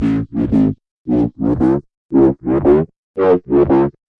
摇摆不定的贝司声 " 贝司重采样5
描述：音乐制作的疯狂低音
Tag: 重采样 重低音 音效设计 摇晃